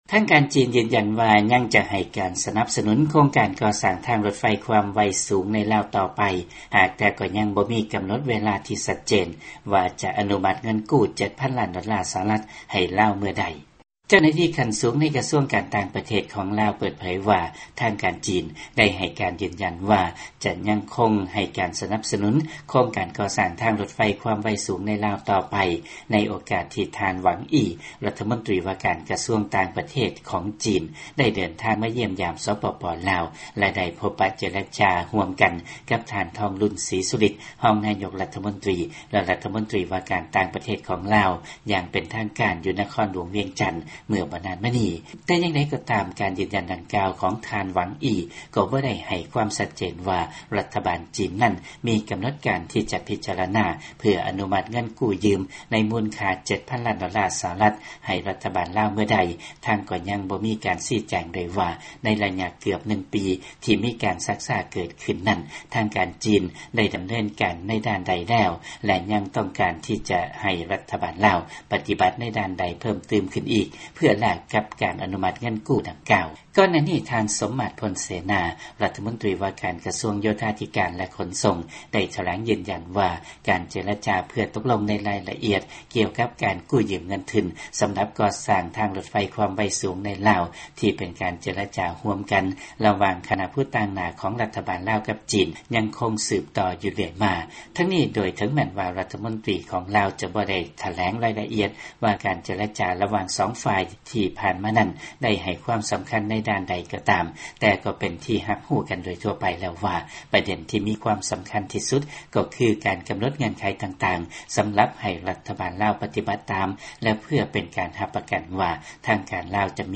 ຟັງລາຍງານໂຄງການສ້າງທາງລົດໄຟ ຄວາມໄວສູງ